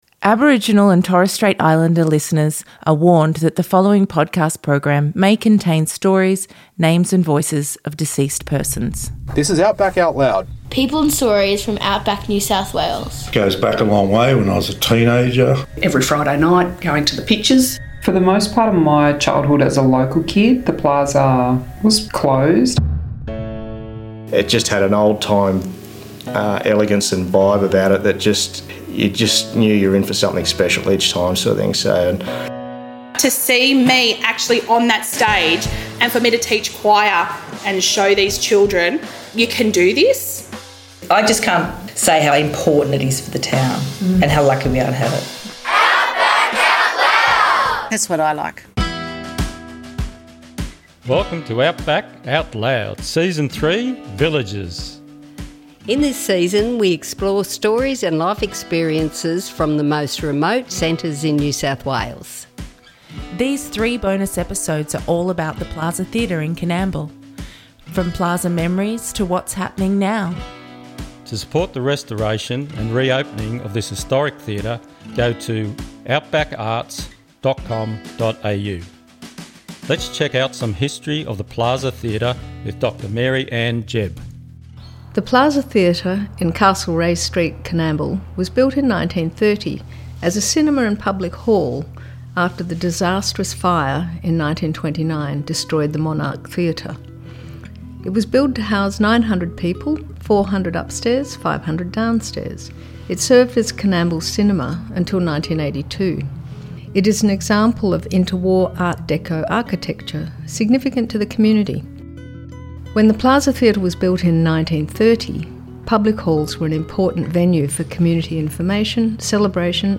In this Bonus Episode we hear interviews from people in Coonamble NSW, Wailwan Country. This episode features stories, memories and reflections about The Plaza Theatre which reopened early 2024.